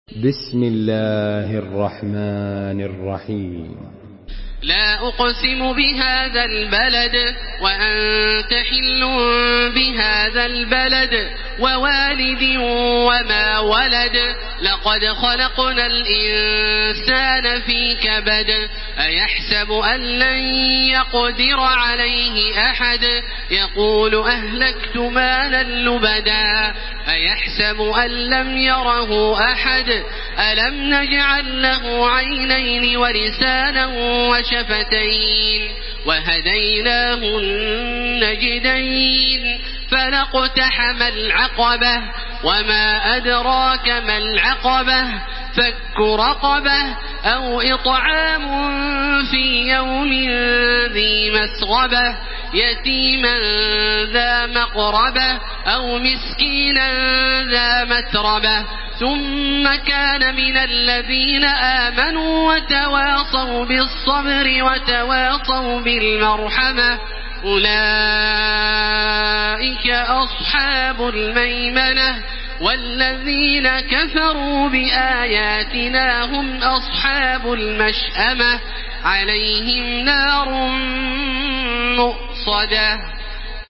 Surah Al-Balad MP3 by Makkah Taraweeh 1434 in Hafs An Asim narration.
Murattal